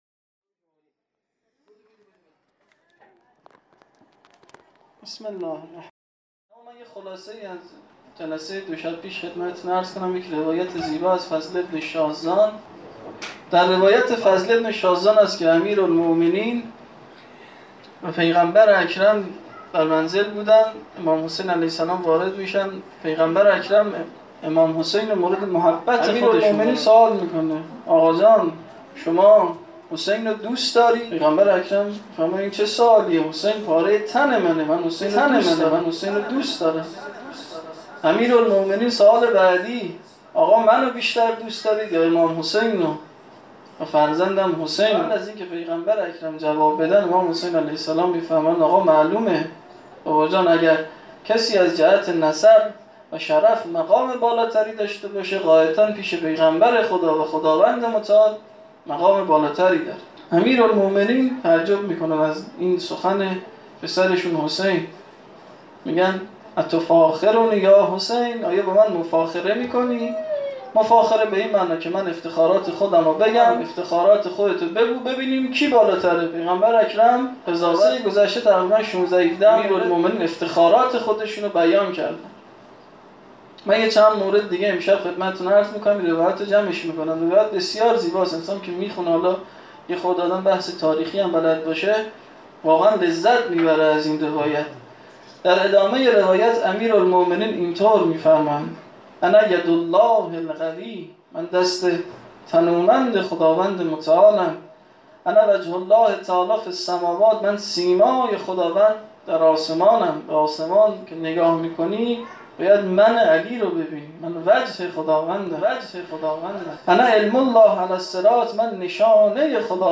هیأت زوارالزهرا سلام اللّه علیها